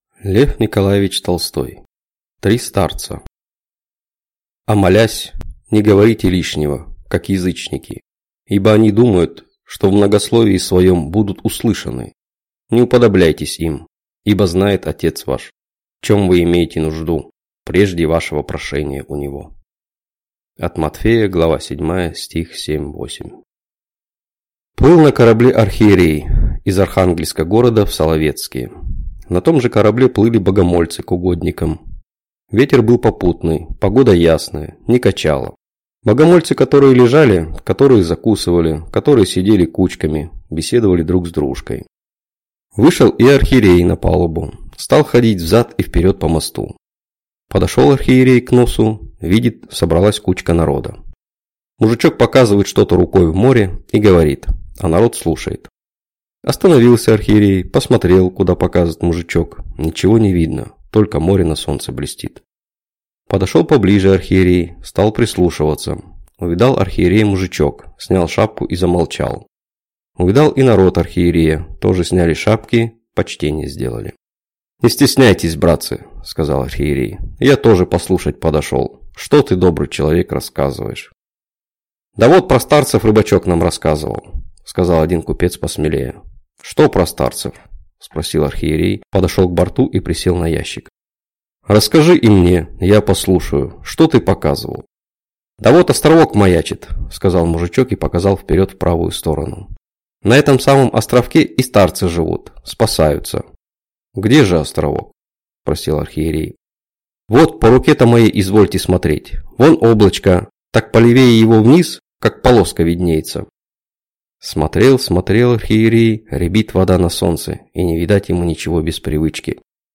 Аудиокнига Три старца | Библиотека аудиокниг